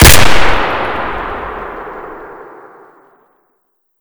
shoot_new.ogg